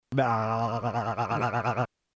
Tags: humor funny sound effects sound bites radio